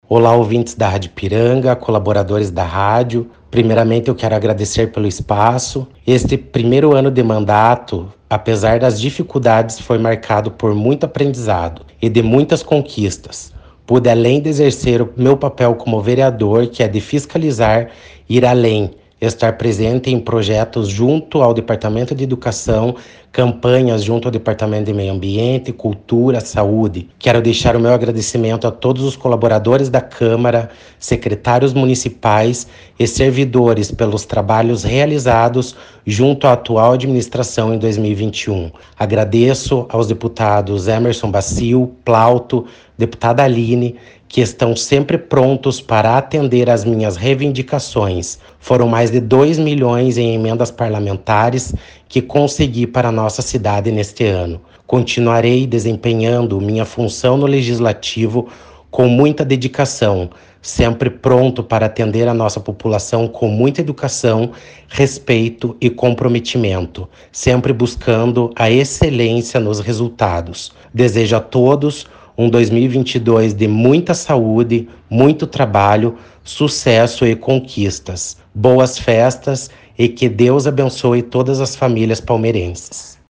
Ouça a fala do vereador Lucas